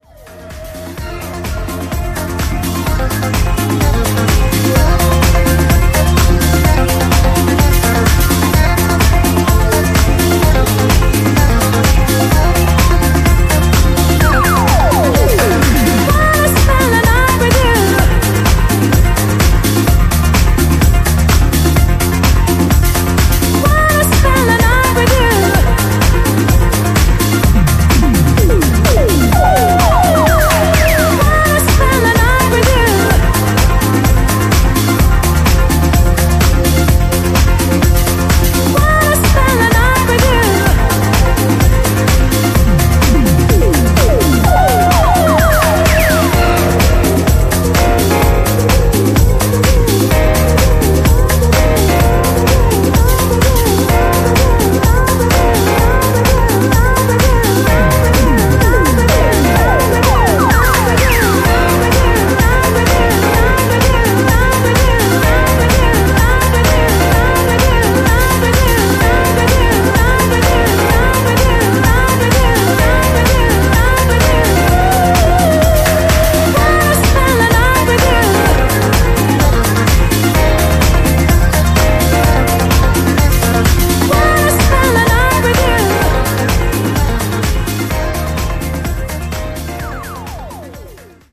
ジャンル(スタイル) HOUSE CLASSIC / UK GARAGE / DEEP HOUSE